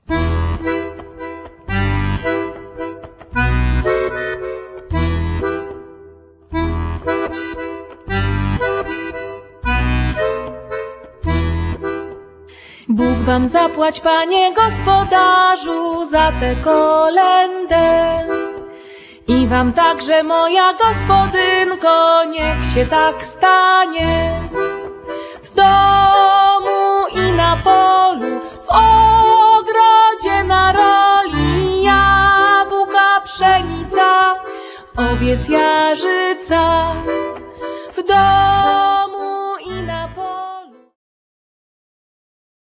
altówka
perkusja